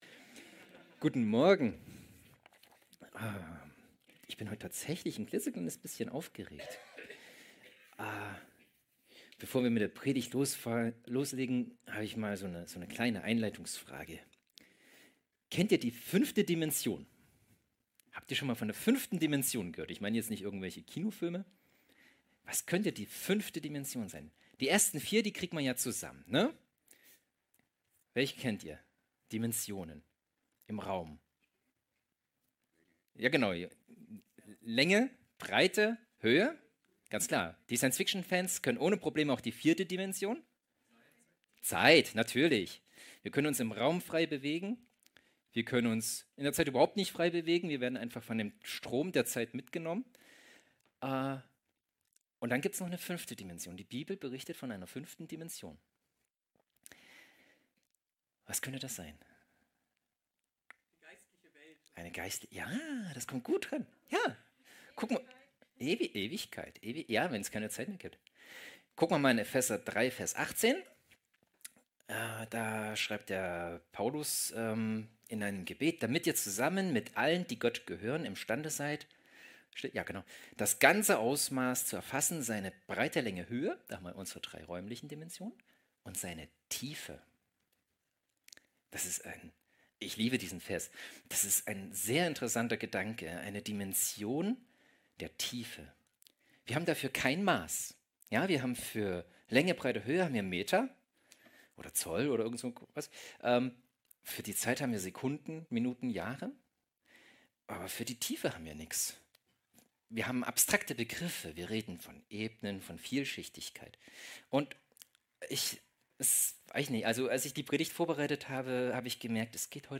Predigten | Panorama Kirche Göppingen :: verändert | gemeinsam | für Andere